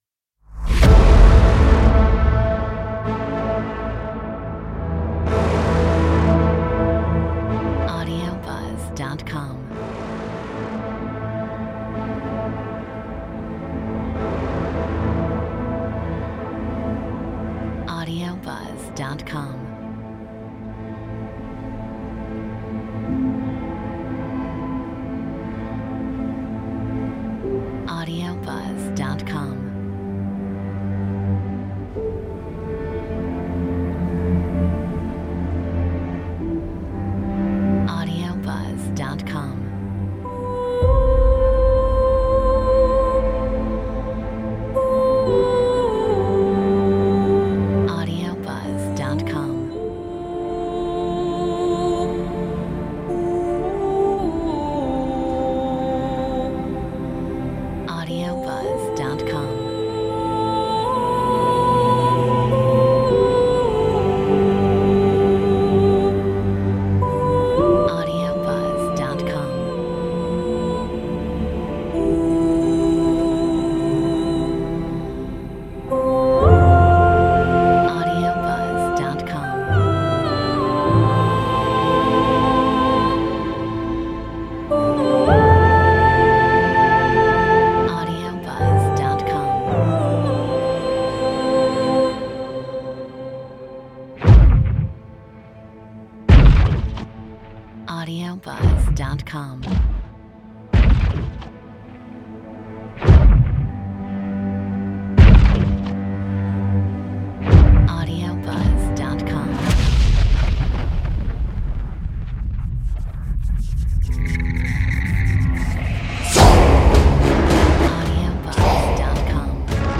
Metronome 108